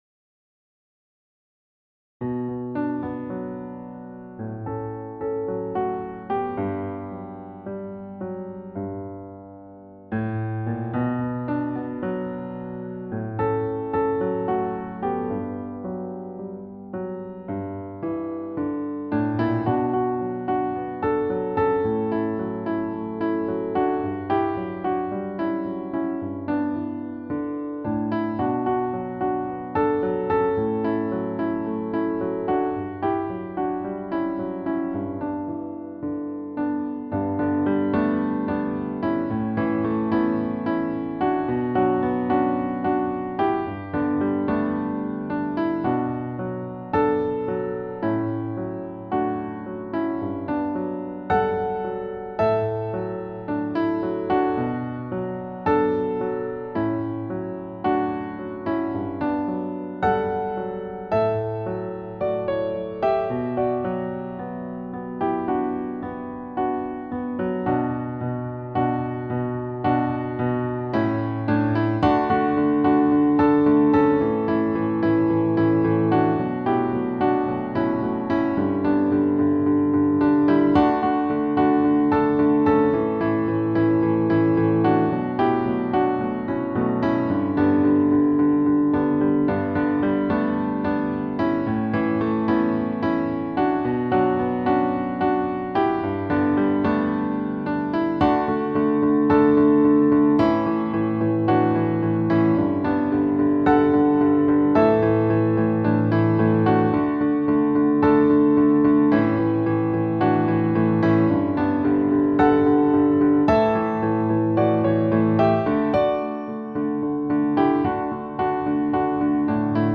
Piano Demo